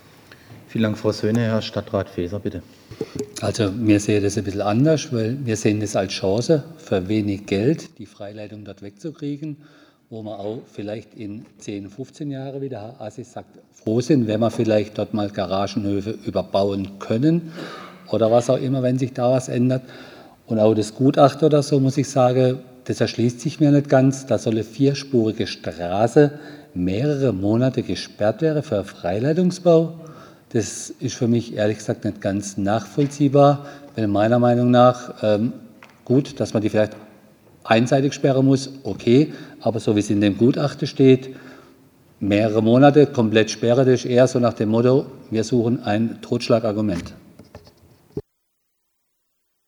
5. BaUStA Ausschuss in Freiburg: Leben unter Hochspannungsleitungen soll bleiben - Verlegung und Vergrabung für Stadt zu teuer